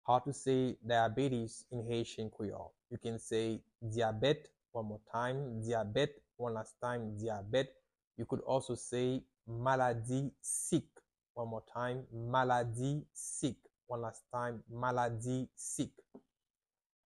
How to say "Diabetes" in Haitian Creole - "Dyabèt" pronunciation by a Haitian Creole teacher
“Dyabèt” or “maladi sik” Pronunciation in Haitian Creole by a native Haitian can be heard in the audio here or in the video below:
How-to-say-Diabetes-in-Haitian-Creole-Dyabet-pronunciation-by-a-Haitian-Creole-teacher.mp3